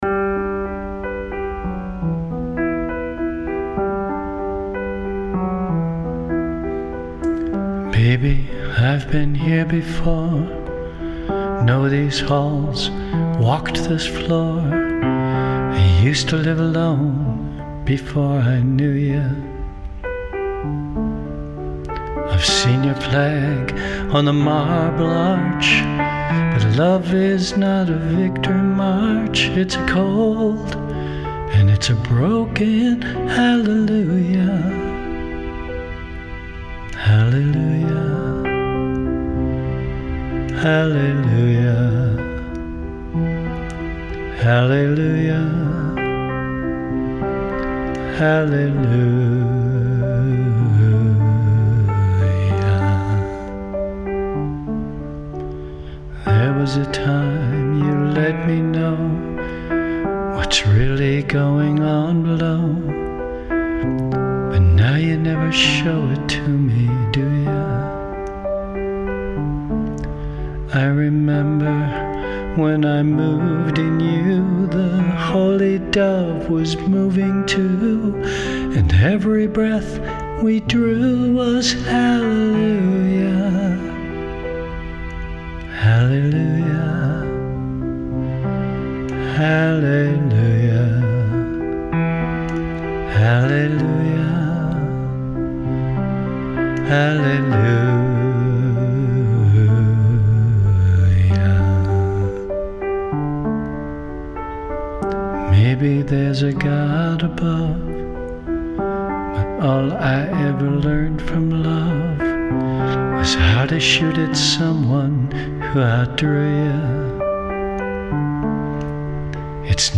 LIVE solo performance
Vocal, Piano